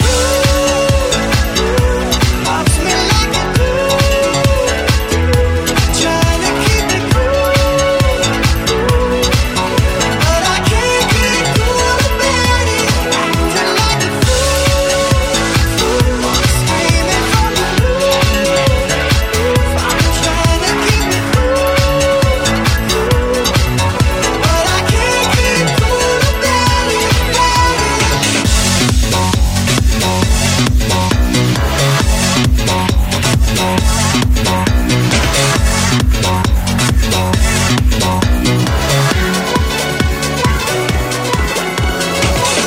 Genere: deep, dance, edm, club, remix